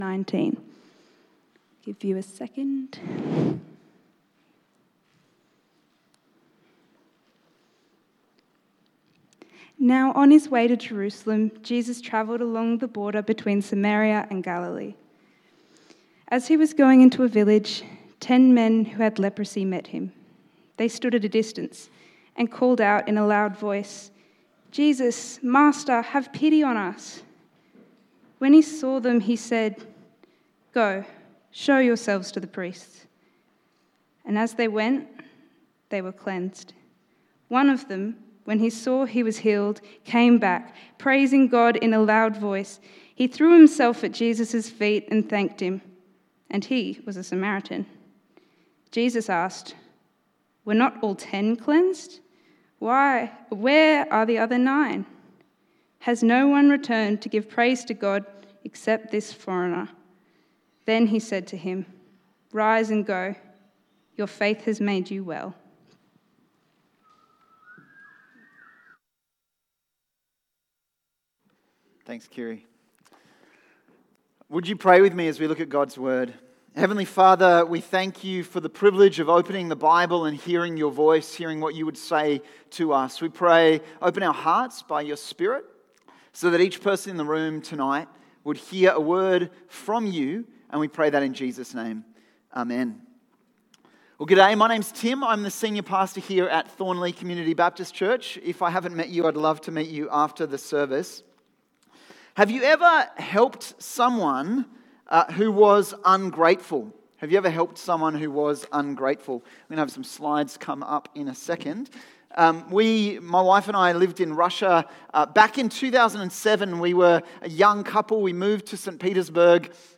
Luke Passage: Luke 17:11-19 Service Type: 6PM